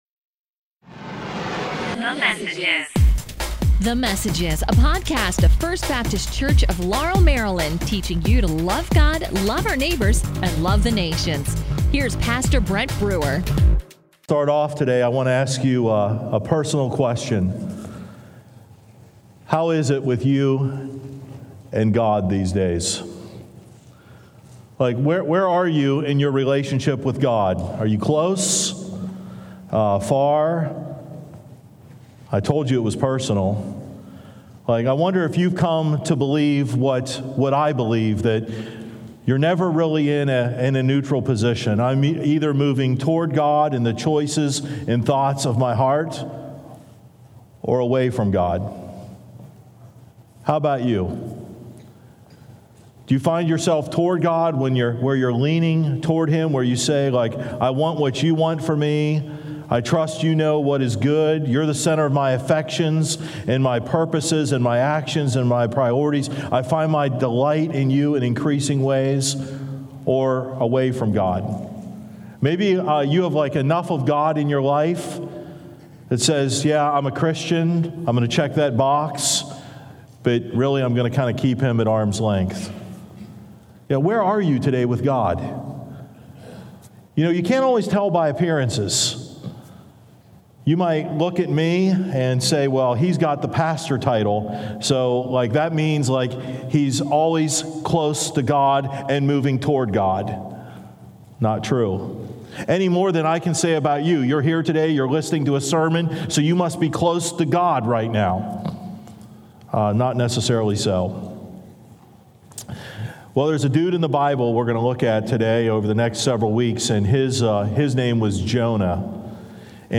A message from the series "Abraham."